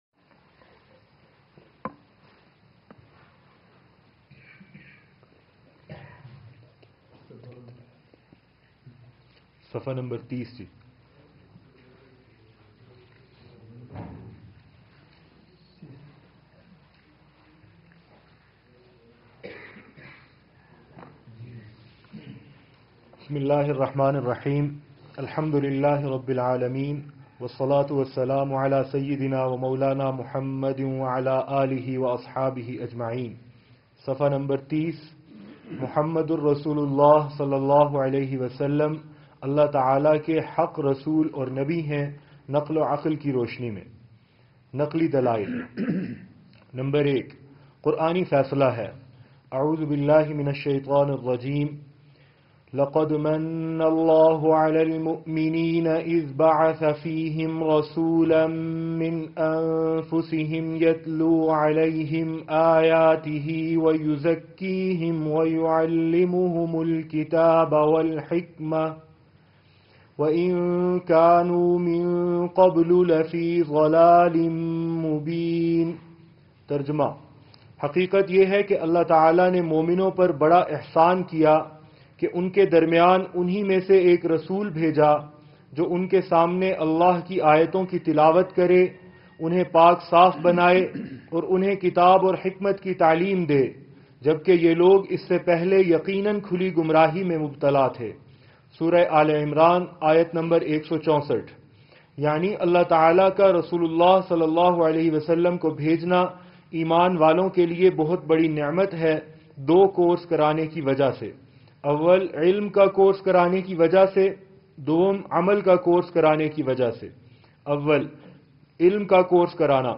اسلامی آڈیو لیکچرز، مجالس، بیانات، اور روحانی مضامین - مدرسہ عربیہ سعدیہ سراجیہ
گہرے روحانی تصورات کی کھوج کرتا ہوا تیسرا اجتماع